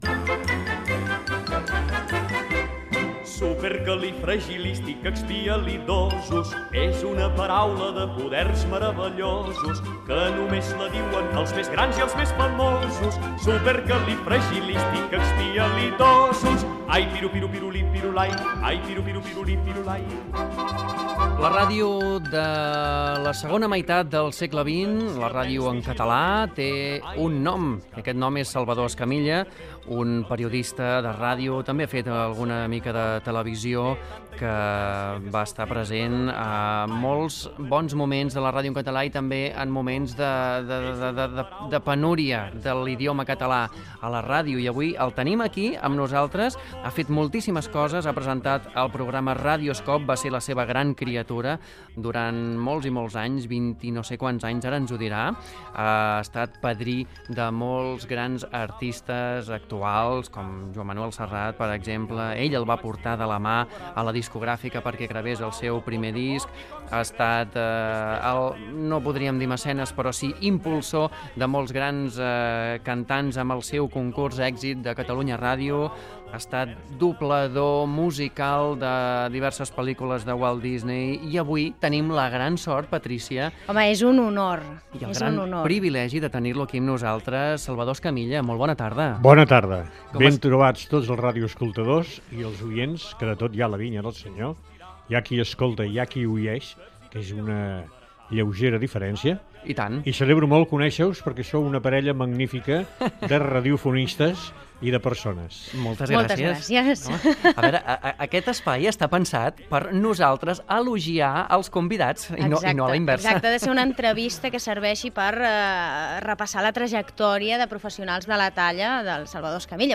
Cançó, perfil biogràfic i entrevista a Salvador Escamilla sobre la seva trajectòria professional
Entreteniment
FM